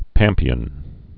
(pămpē-ən, păm-pēən)